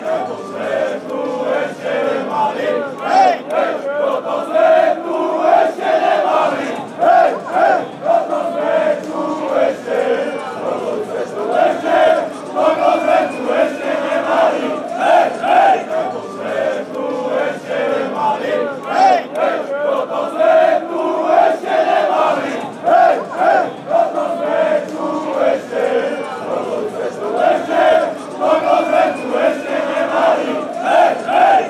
A Slovakia soccer chant.